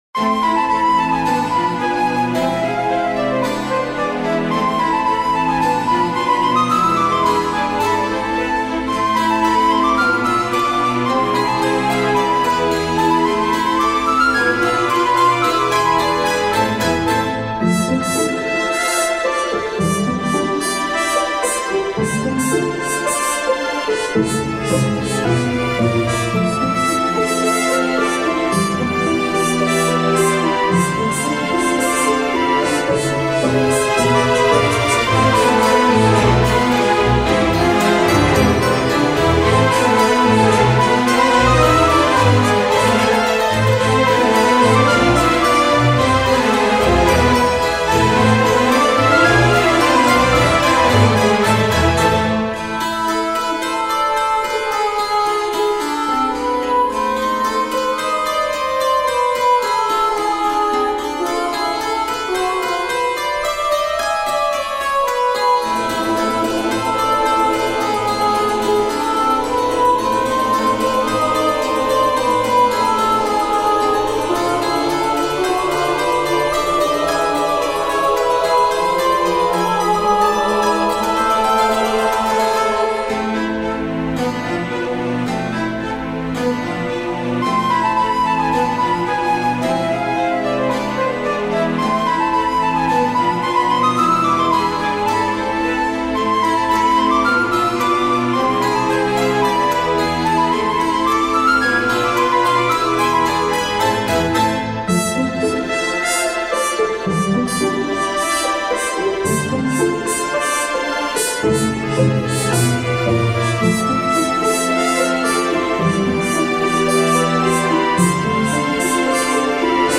ジャンルクラシック
BPM１１０
使用楽器フルート、トランペット、ストリングス、ボイス
解説フルートや声楽を用いた哀愁漂う室内楽のフリーBGMです。
中世ヨーロッパの哀愁漂うメロディーに、異国のエッセンスを加えております。
室内楽(Chamber)